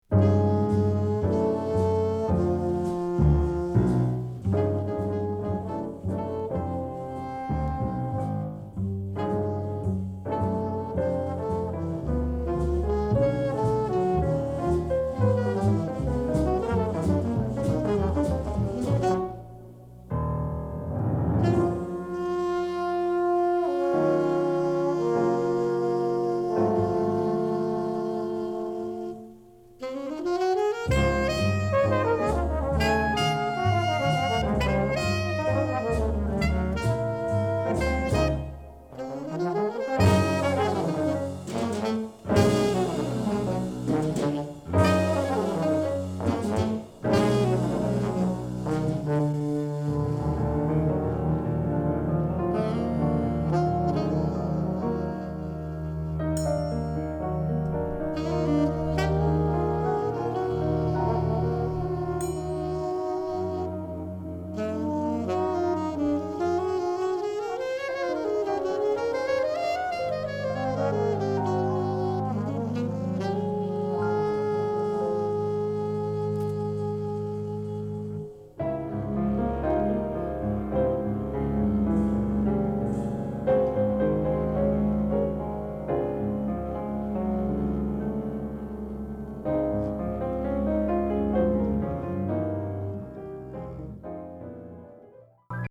Alto Saxophone
Drums
Tenor Saxophone
Trombone
Soul